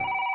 ringring.wav